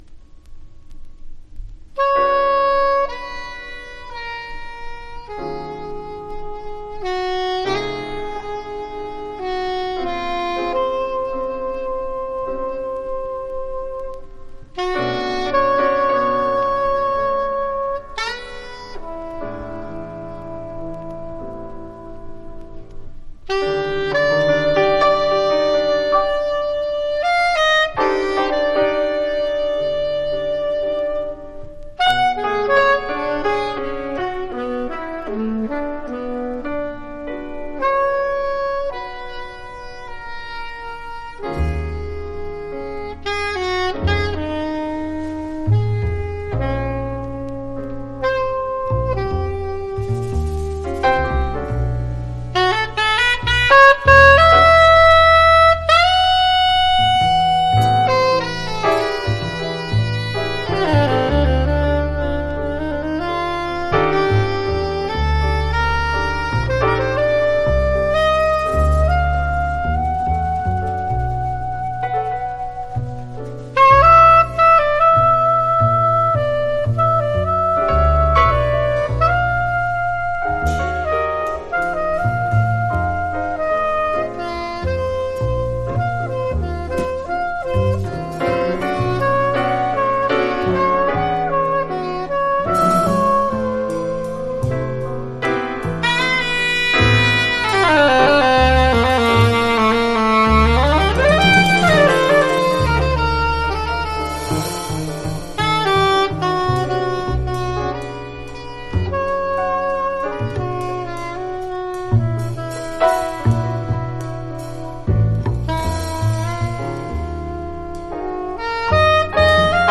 （プレスによりチリ、プチ音ある曲あり）
Genre FREE/SPIRITUAL JAZZ